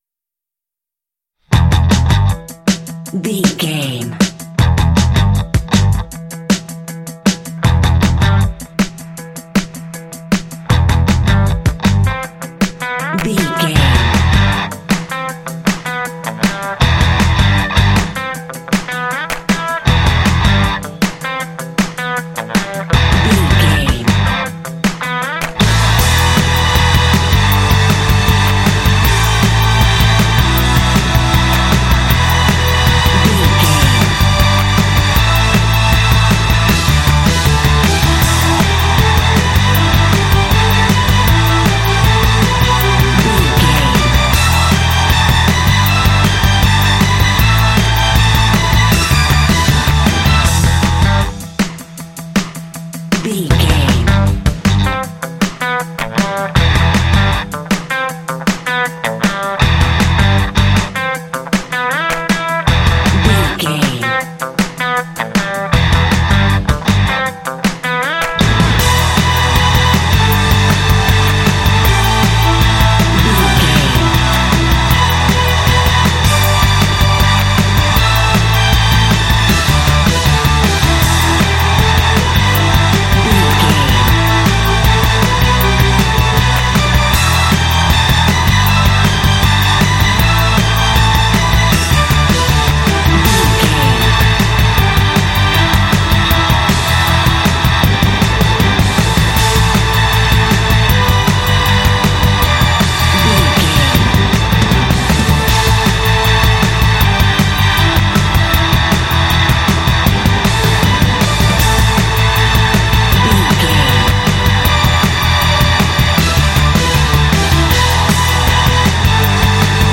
Aeolian/Minor
Fast
driving
intense
powerful
energetic
bass guitar
electric guitar
drums
strings
heavy metal
alternative rock
symphonic rock